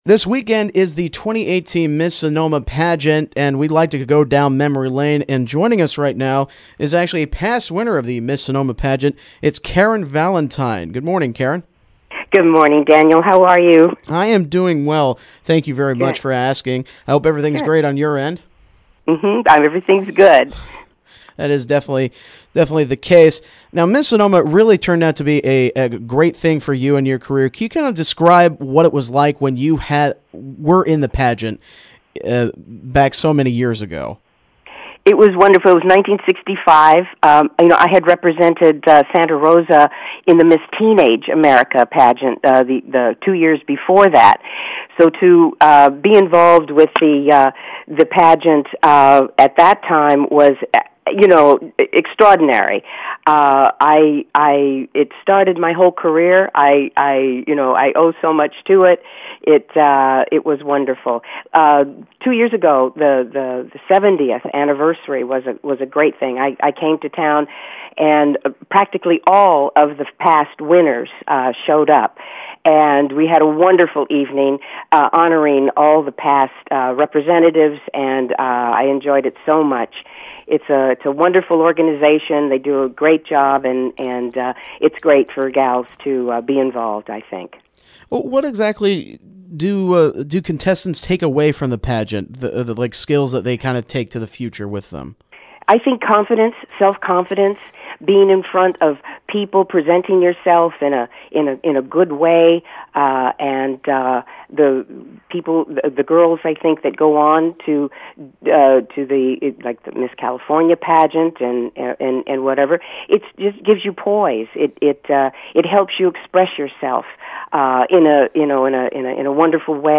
Interview: Legendary Winner of Miss Sonoma County, Karen Valentine